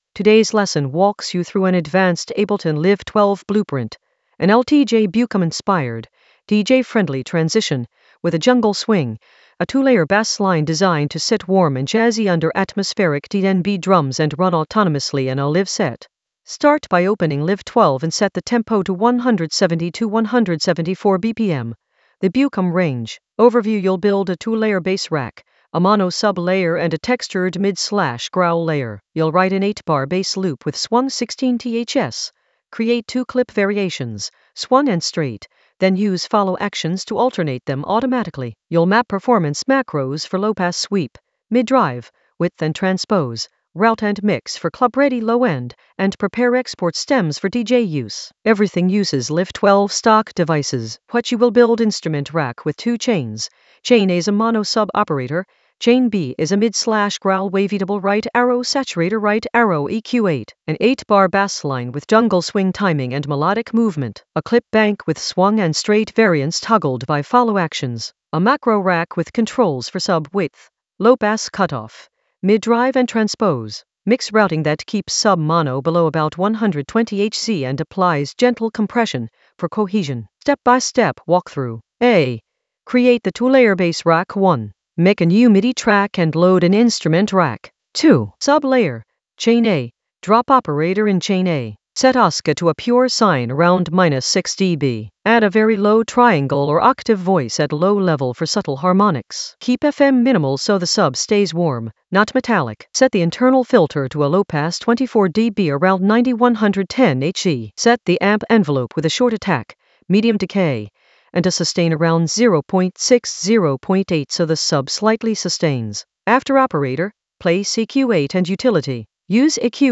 An AI-generated advanced Ableton lesson focused on LTJ Bukem Ableton Live 12 DJ-friendly transition blueprint with jungle swing in the Basslines area of drum and bass production.
Narrated lesson audio
The voice track includes the tutorial plus extra teacher commentary.